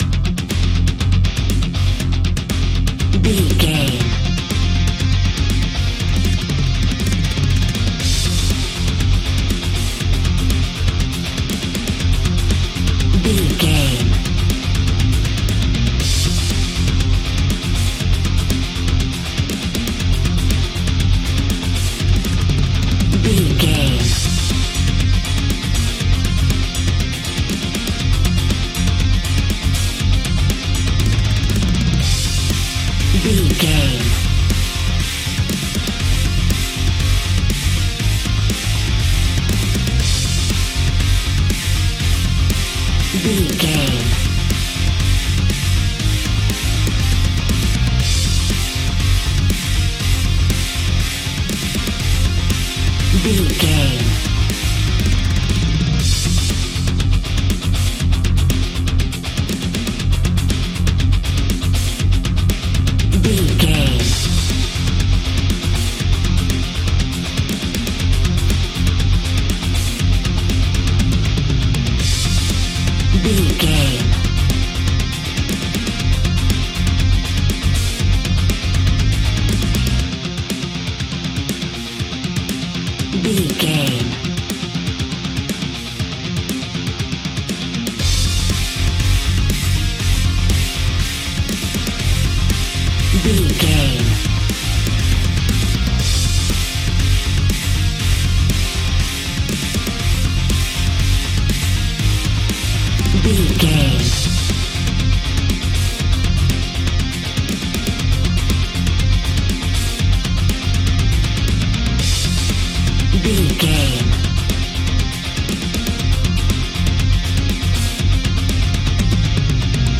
Epic / Action
Fast paced
Aeolian/Minor
intense
ominous
dark